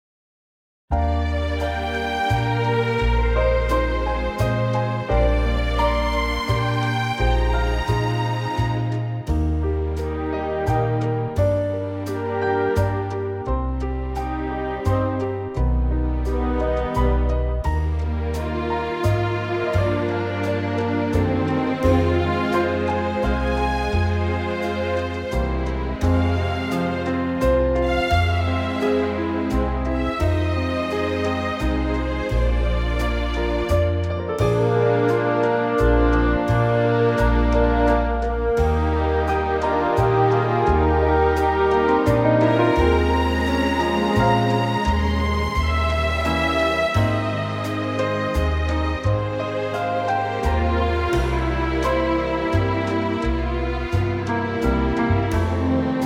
Unique Backing Tracks
key - Eb - vocal range - Bb to C
Gorgeous orchestral arrangement of this lovely waltz.